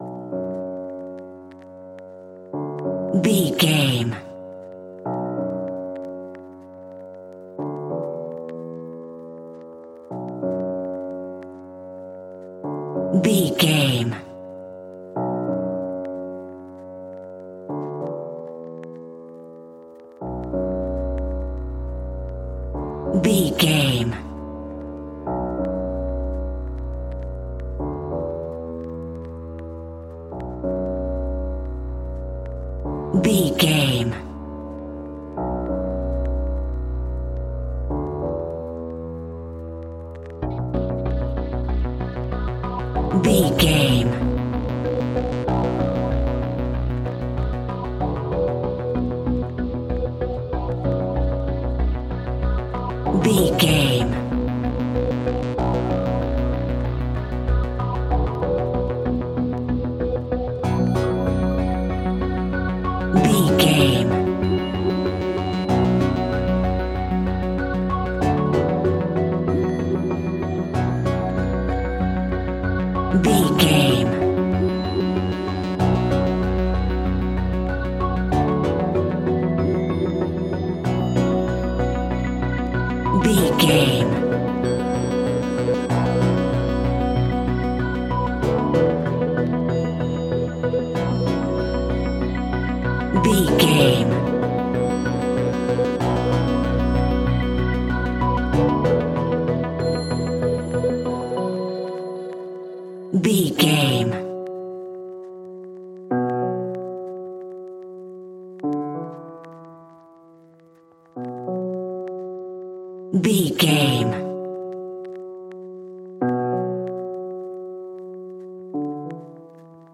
In-crescendo
Aeolian/Minor
ominous
haunting
eerie
electronic music
Horror Pads
Horror Synths